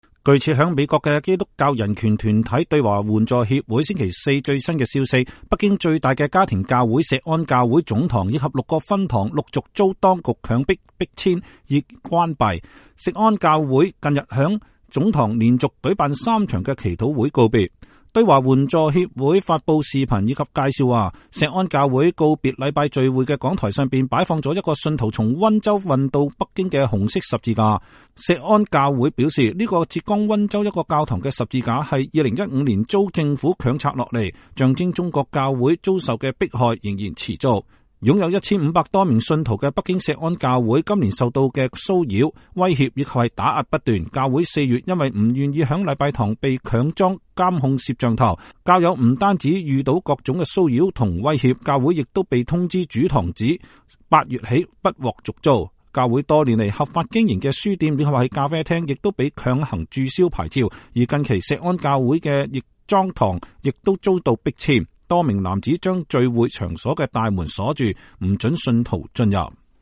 北京錫安教會告別祈禱聚會